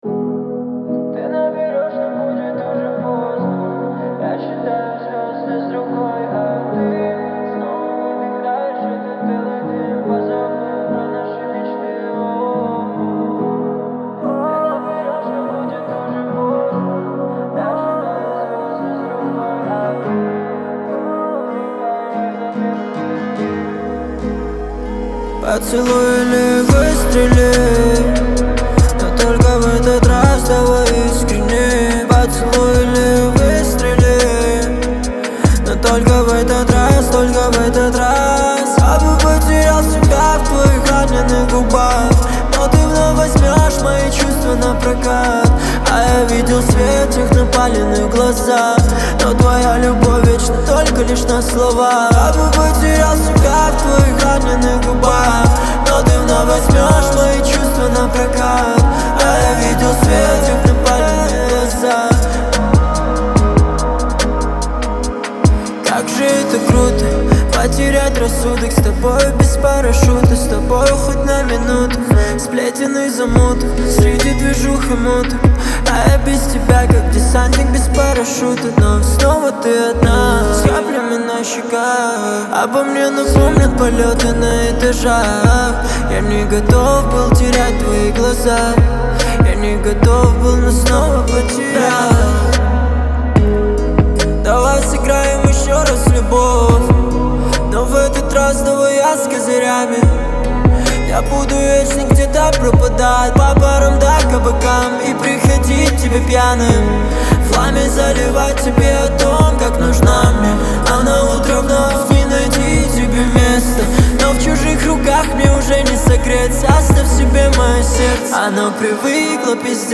это энергичная поп-рок композиция